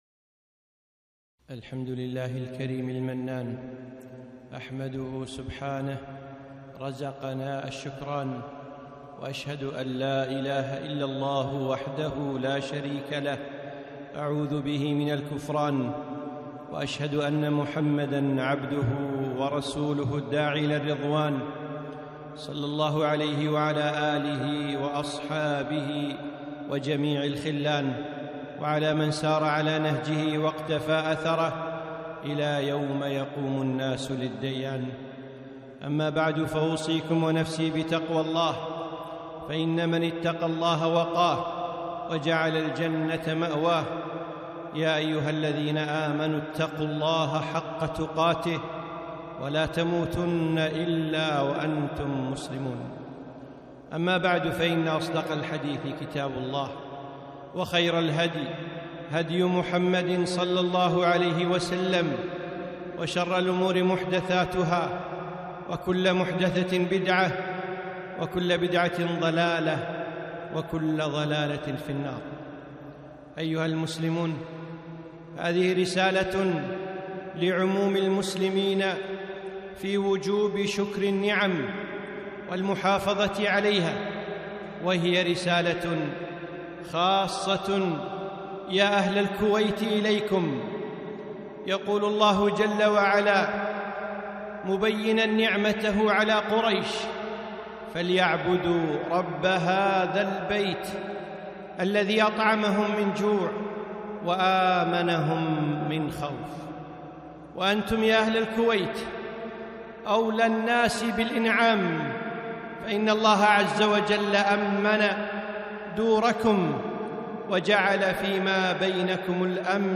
خطبة - يا أهل الكويت